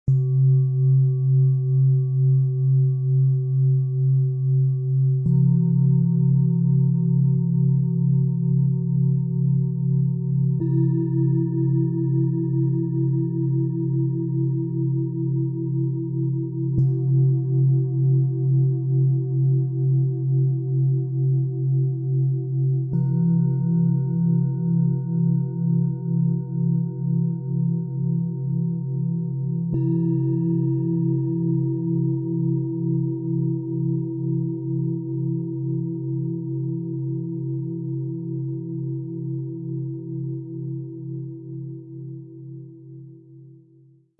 Freundliche Vitalität, tiefe Geborgenheit und Herzenswärme - Set aus 3 Planetenschalen, Ø 15,2 -20,9 cm, 2,46 kg
Tiefster Ton: Biorhythmus Körper - Sanfte Vitalität
Diese Schale hat eine weiche, sanfte Vibration, die wunderbar für Kleinmassagen geeignet ist.
Mittlerer Ton: Mond - Tiefe emotionale Berührung
Höchster Ton: Hopi Herzton - Freundliche Herzlichkeit
Der Hopi Herzton zeichnet sich durch seine harmonischen, freundlichen Schwingungen aus, die eine liebevolle Verbindung fördern.